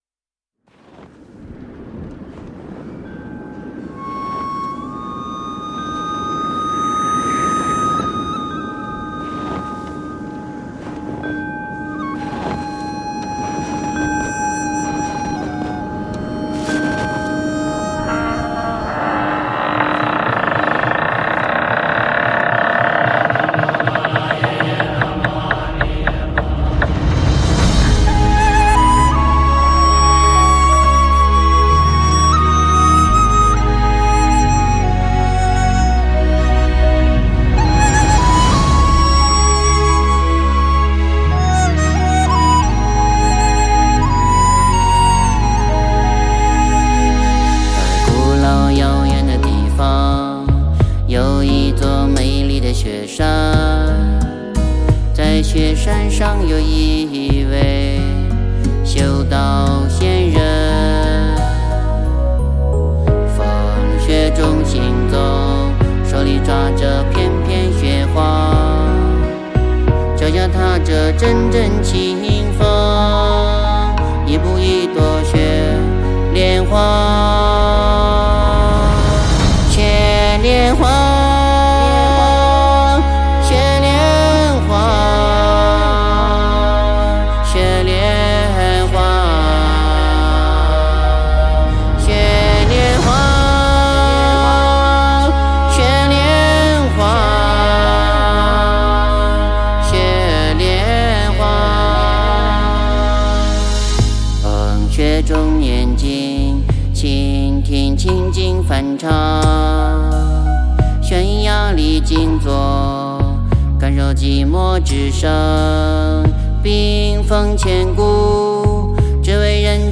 佛音 冥想 佛教音乐 返回列表 上一篇： 清净法身佛--天籁梵音 下一篇： 06.